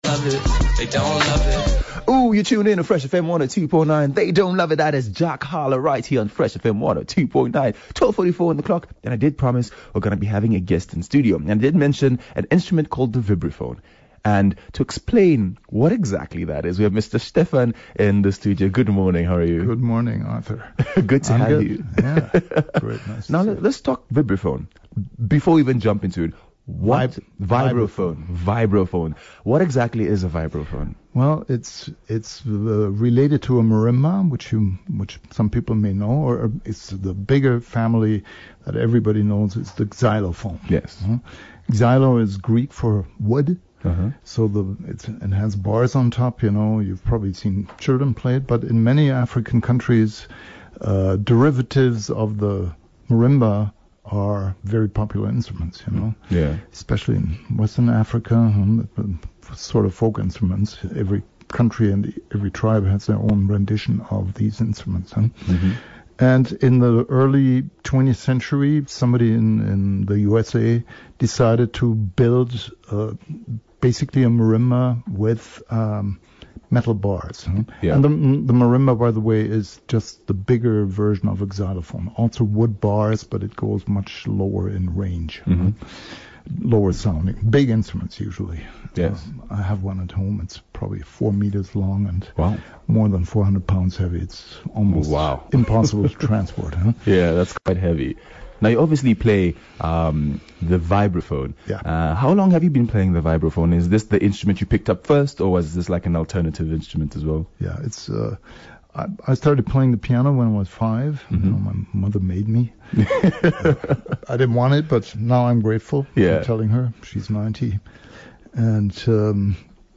12 Jun vibraphone experience